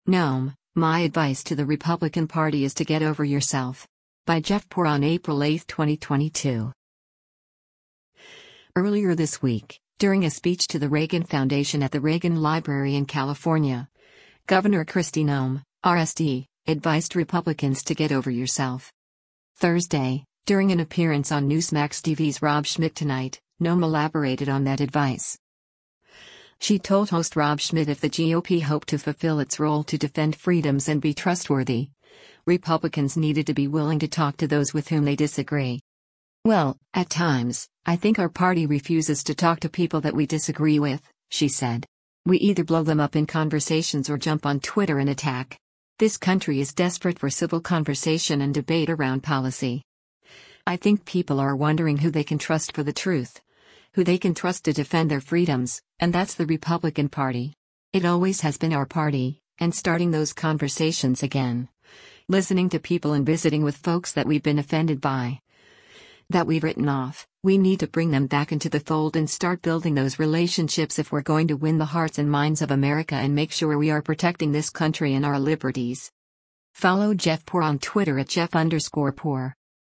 Thursday, during an appearance on Newsmax TV’s “Rob Schmitt Tonight,” Noem elaborated on that advice.
She told host Rob Schmitt if the GOP hoped to fulfill its role to defend freedoms and be trustworthy, Republicans needed to be willing to talk to those with whom they disagree.